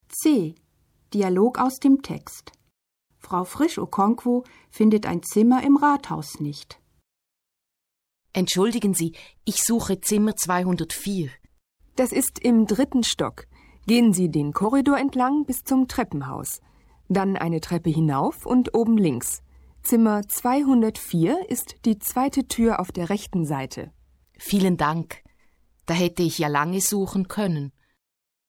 Dialog aus dem Text: Frau Frisch-Okonkwo findet ein Zimmer im Rathaus nicht (456.0K)